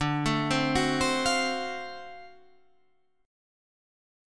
D7#9 Chord